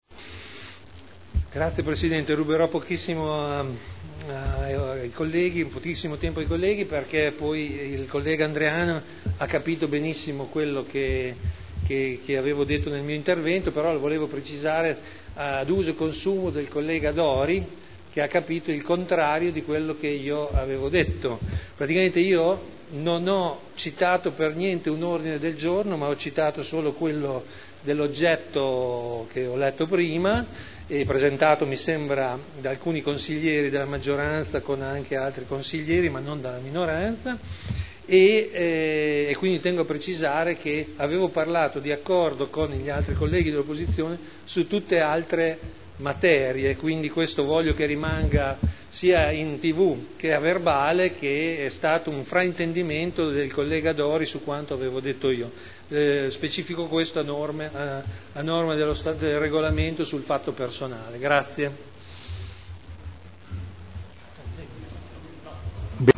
Seduta del 18 ottobre 2010 - Interviene per questione personale.